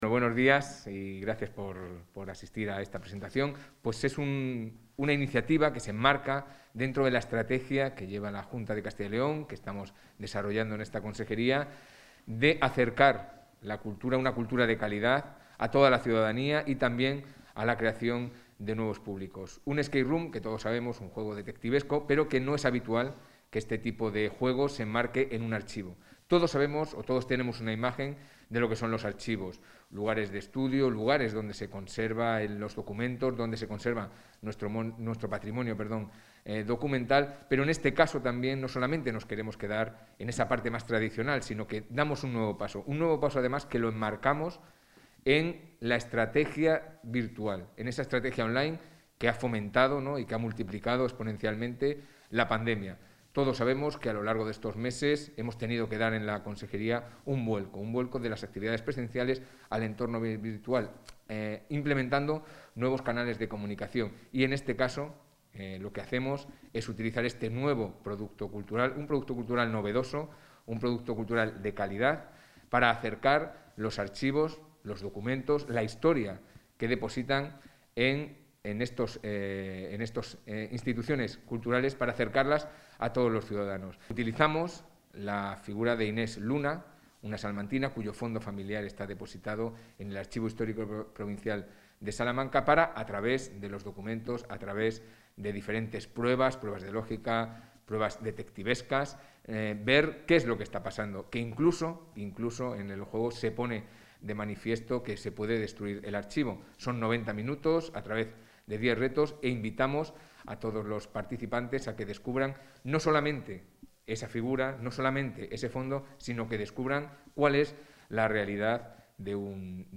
Intervención del consejero de Cultura y Turismo.